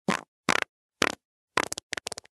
Звуки пердежа, пукания
Короткие пуки человека